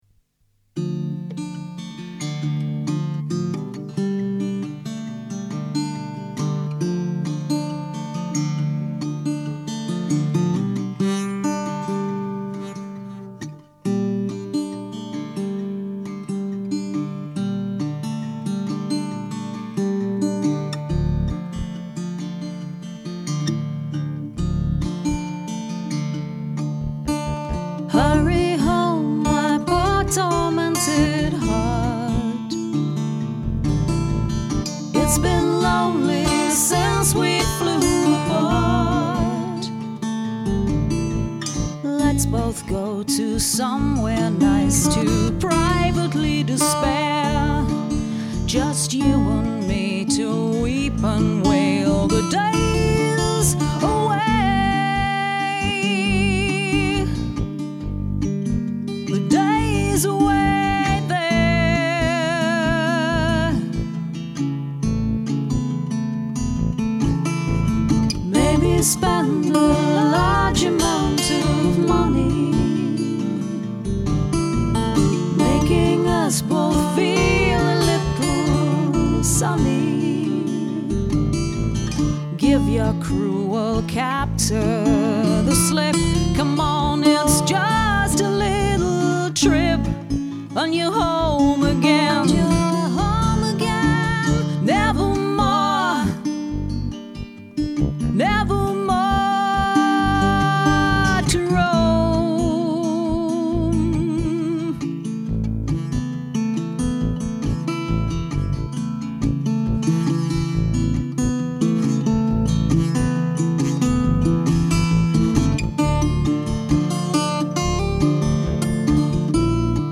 Vocals
Guitars